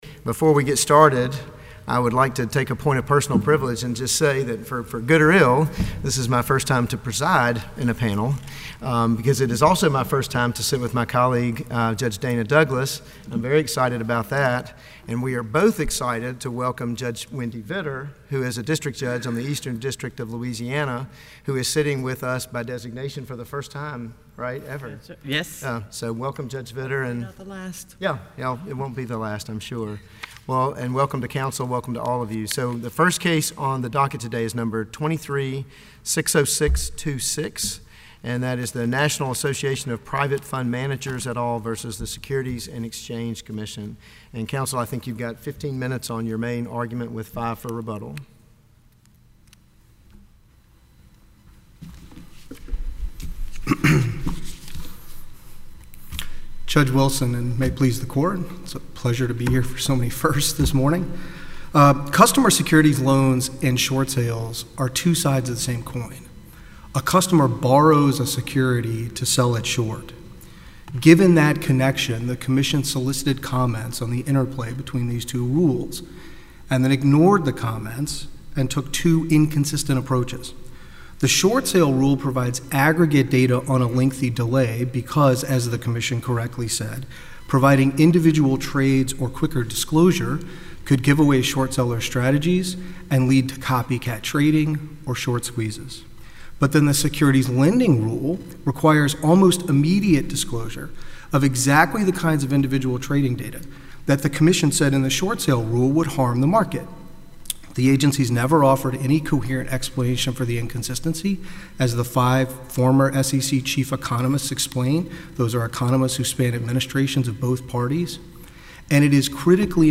On October 7, oral argument in the AIMA, NAPFM and MFA challenge to the SEC’s Securities Lending and Short Sale Rules (“SL/SS Rules”) took place at the U.S. Fifth Circuit Court of Appeals in New Orleans.